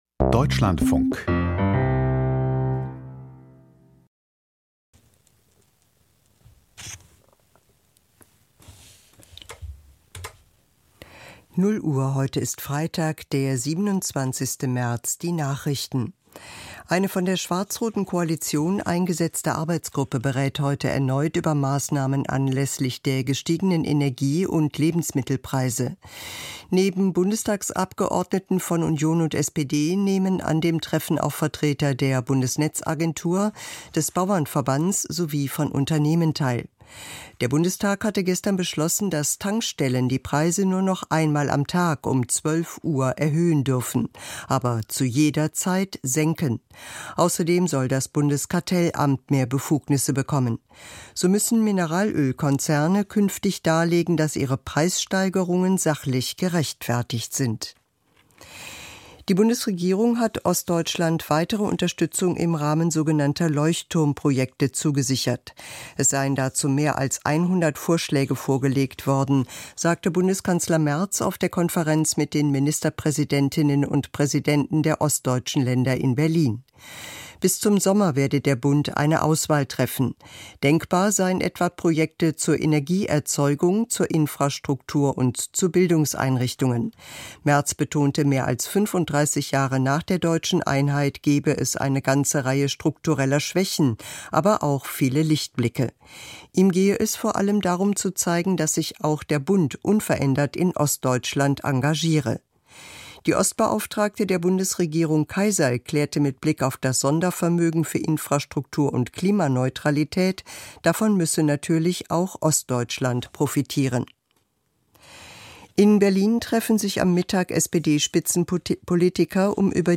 Die Nachrichten vom 27.03.2026, 00:00 Uhr
Aus der Deutschlandfunk-Nachrichtenredaktion.